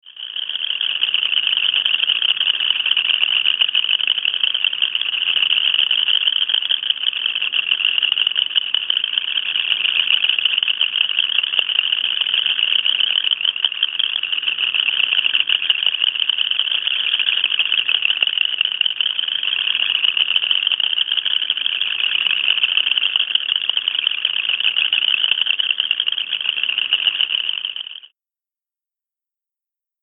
Boreal Chorus Frog (Pseudacris maculata)